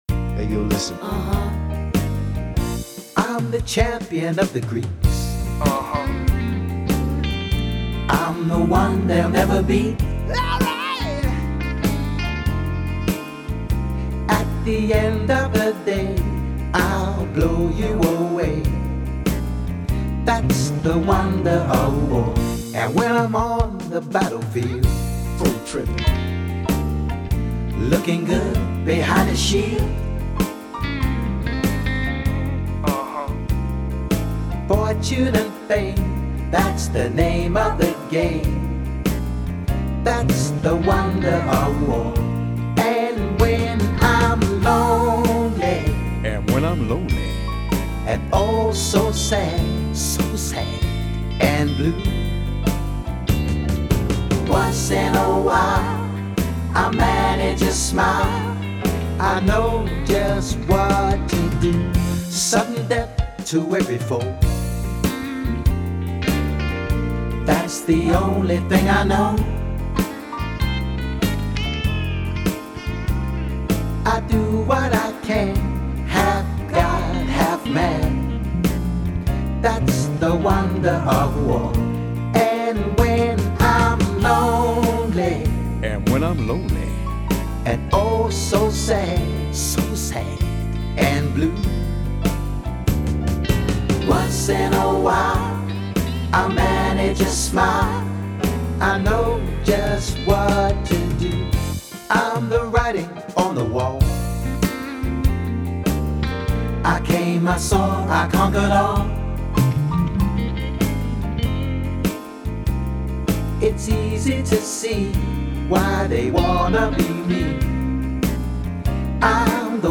Song style: pop